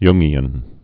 (yngē-ən)